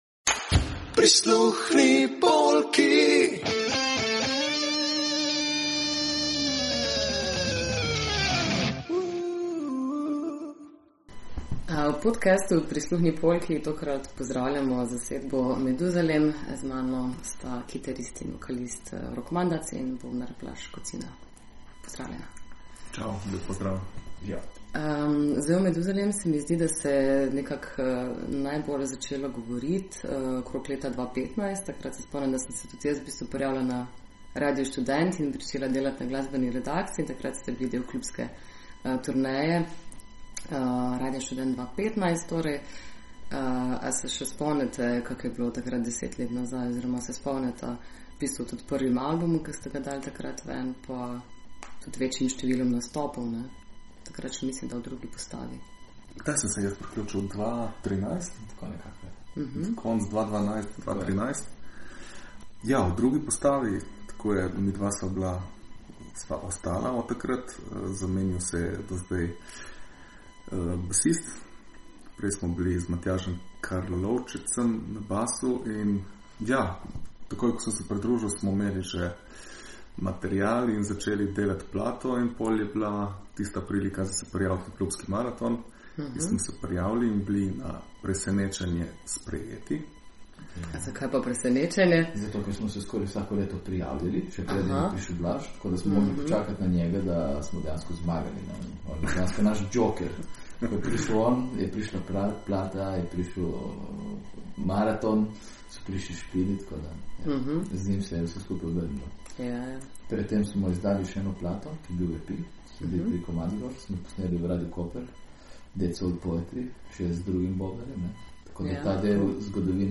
Intervju_MeduzaleM-1.mp3